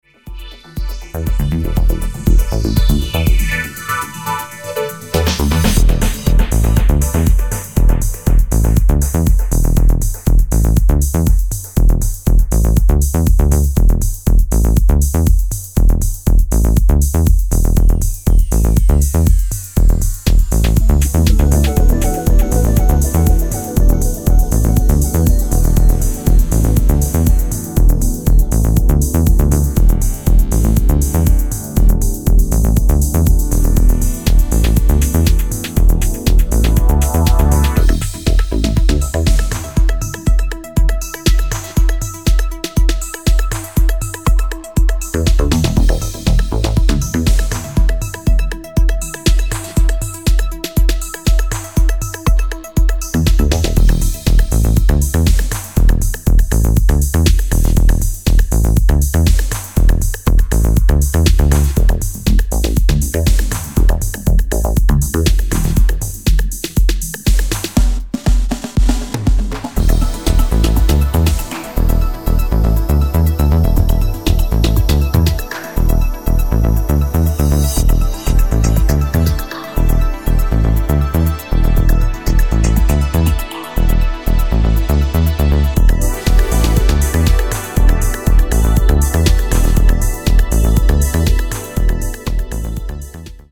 耳障りの良いシンセにハマれる秀作です！